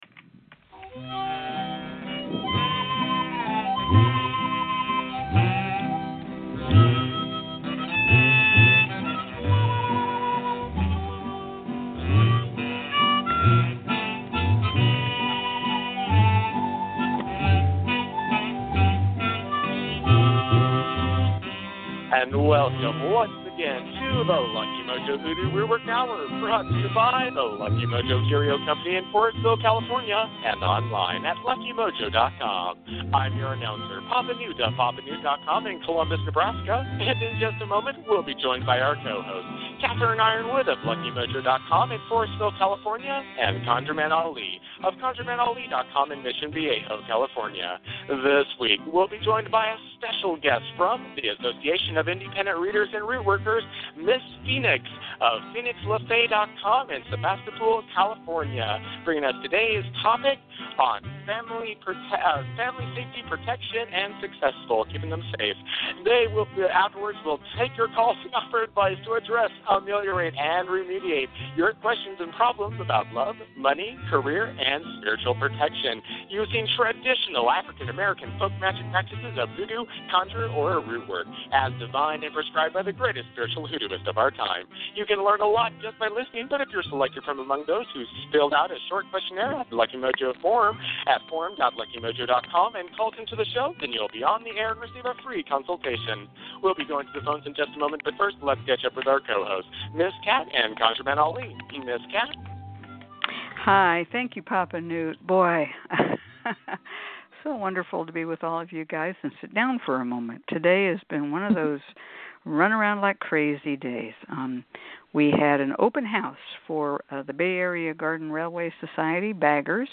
followed by free psychic readings, hoodoo spells, and conjure consultations